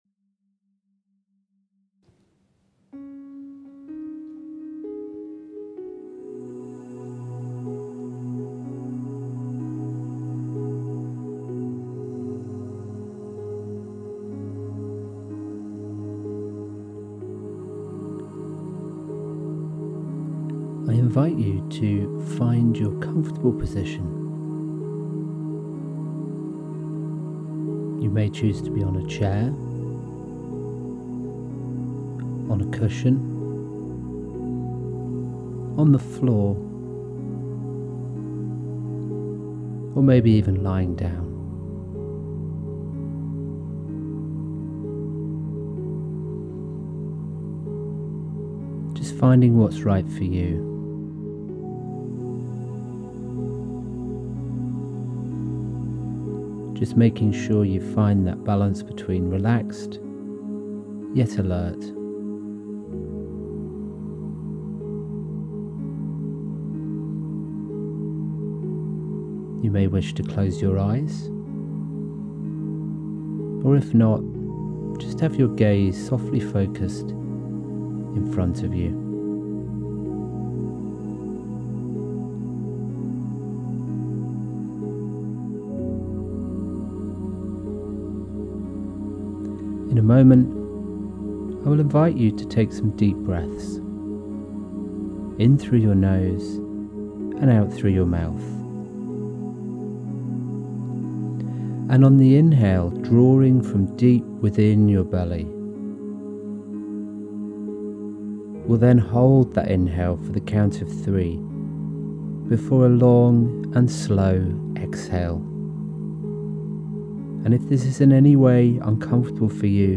This meditation series is not specifically focused on Covid-19 and can be re-played at any time you wish, I do recommend watching in sequence if you can.